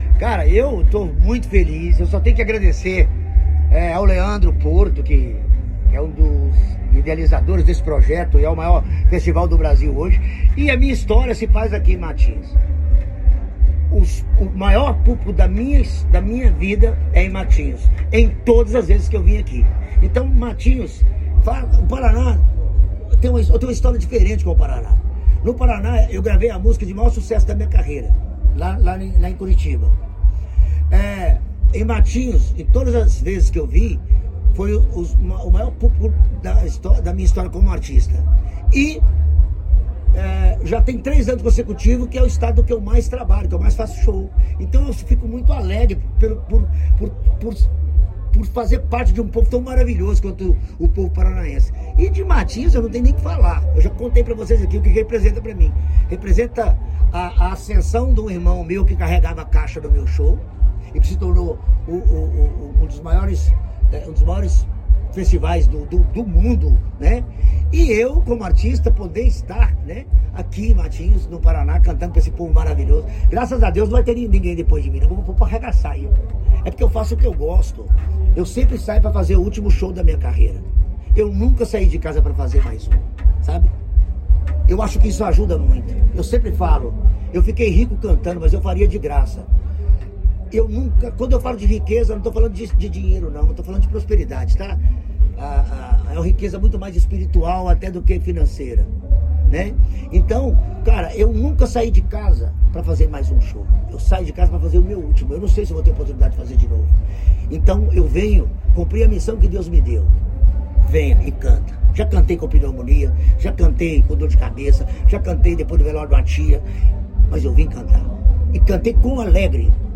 Sonora do cantor Eduardo Costa sobre o show em Matinhos no Verão Maior Paraná